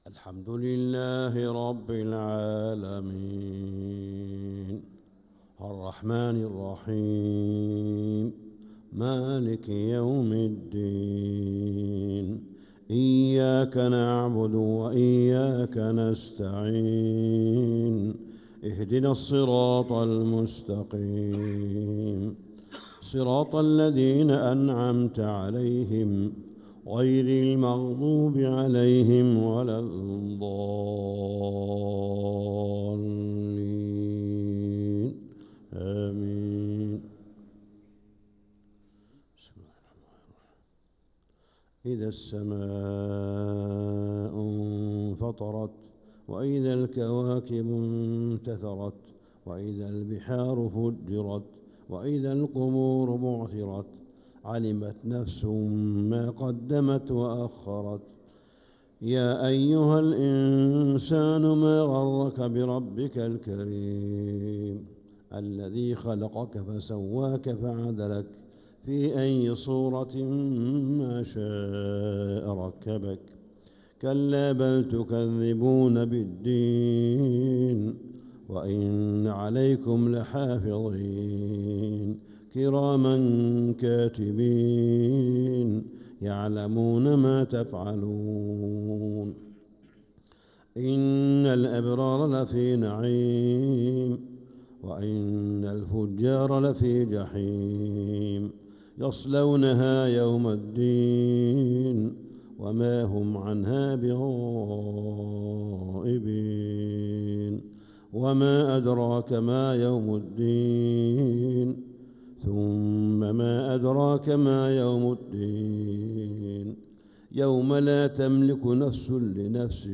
فجر الأربعاء 5-9-1446هـ سورتي الإنفطار و الليل كاملة | Fajr prayer Surat al-Infitar & al-Layl 5-3-2025 > 1446 🕋 > الفروض - تلاوات الحرمين